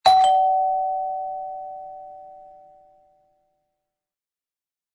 Carillon Festival 8V blanc
génération de son: mécanique
fonction complémentaire: double son / volume sonore: 80 dB